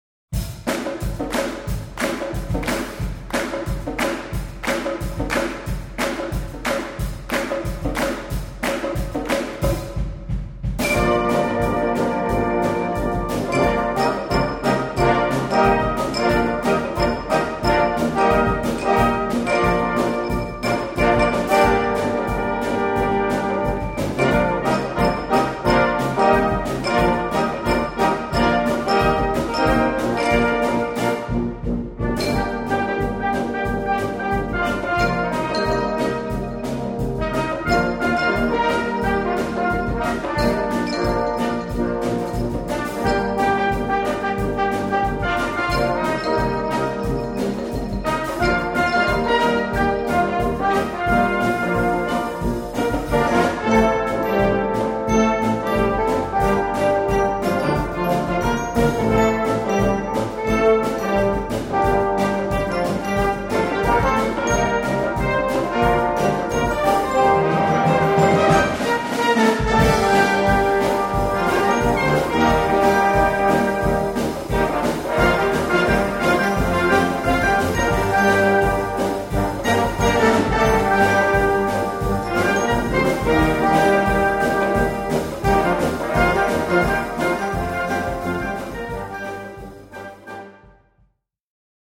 4:00 Minuten Besetzung: Blasorchester PDF